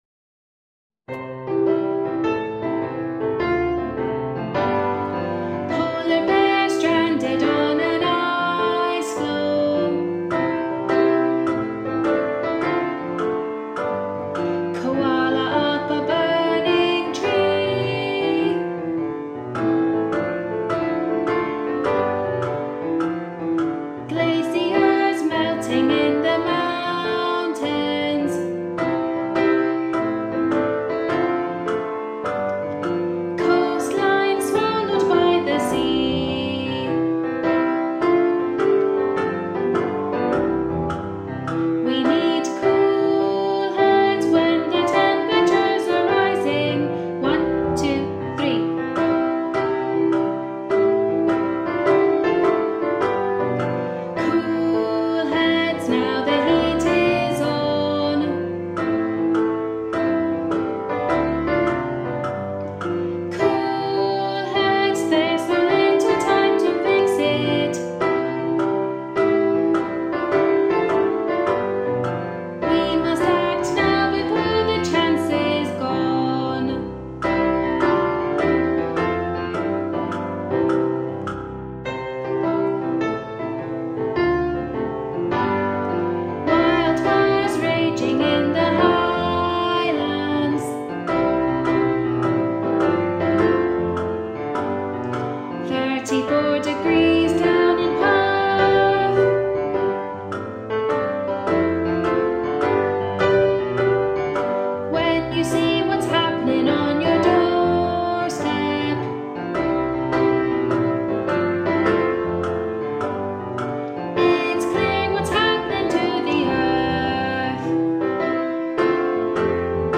Teaching Track
Cool-Heads-Teaching-Track.m4a